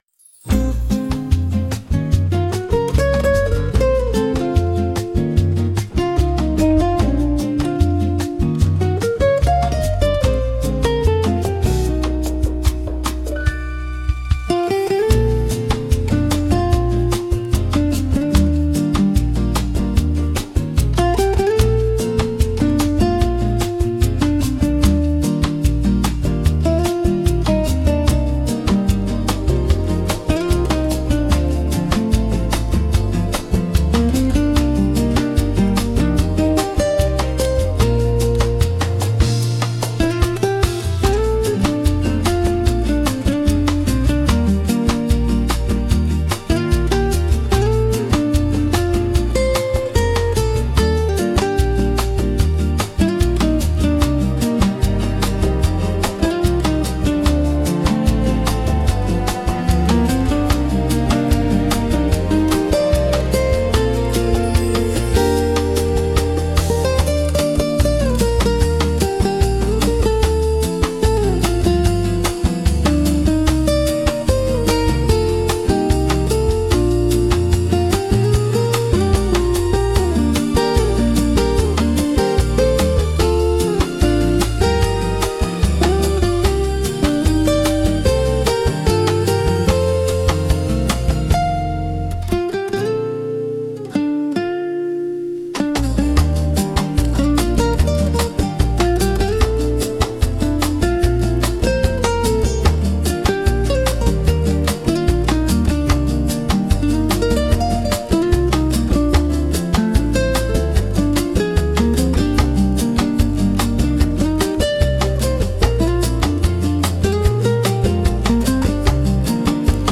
Relaxing Bossa Instrumental